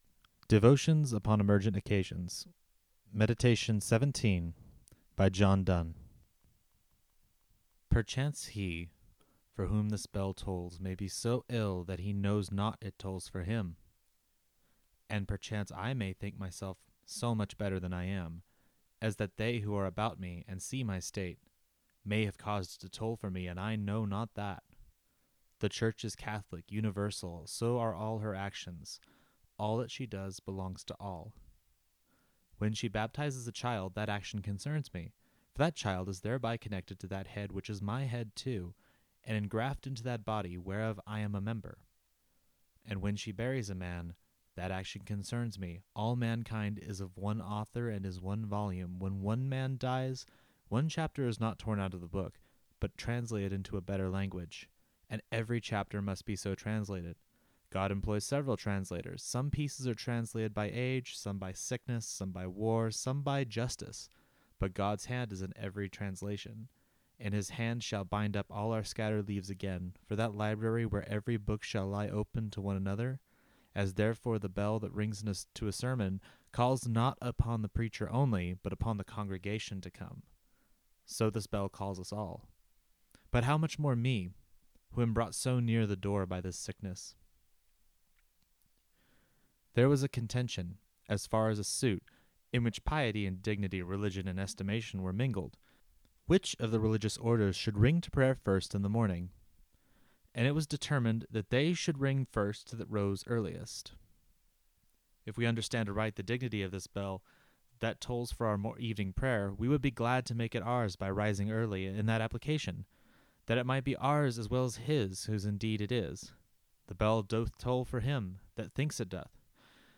I may start doing more of these, but here is a reading of one of my favorite things.